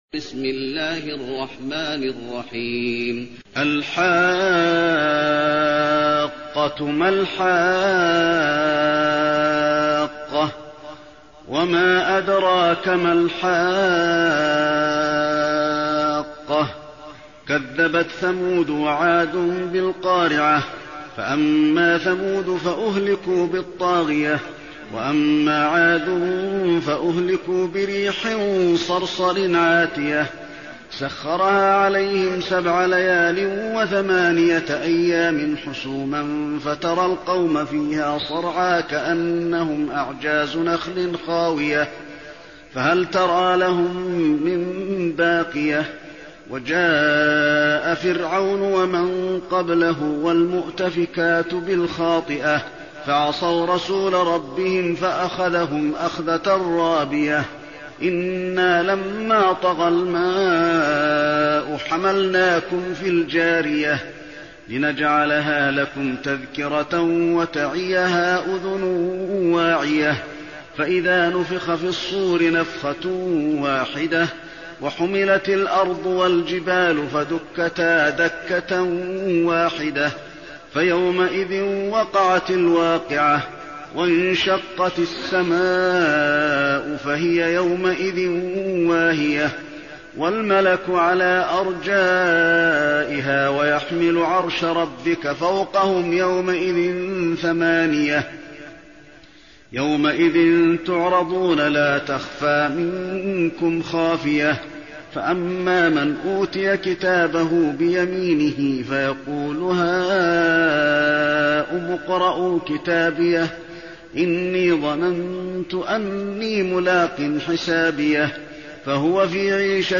المكان: المسجد النبوي الحاقة